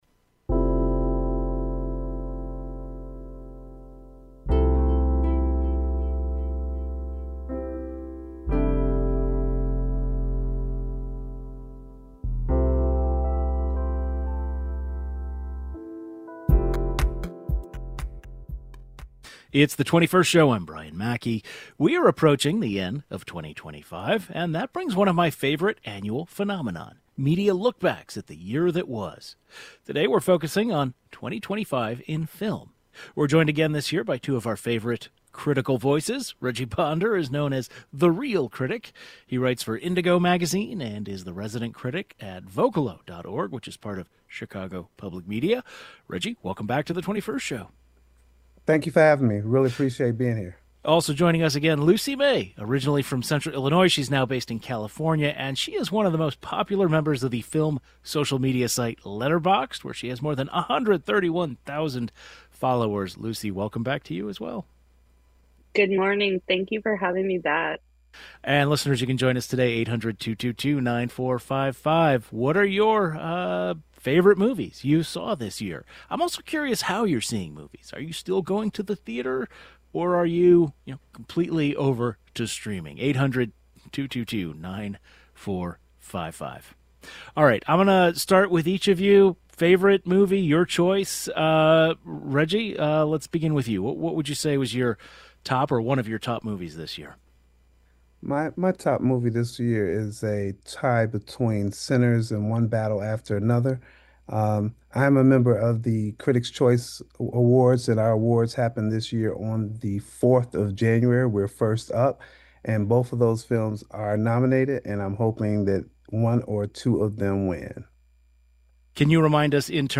The 21st Show is Illinois' statewide weekday public radio talk show, connecting Illinois and bringing you the news, culture, and stories that matter to the 21st state.
As 2025 approches its end, two film critics look back at all the different movies that were released this year. They share their picks for the best, the worst, the most overhyped, and the most underrated.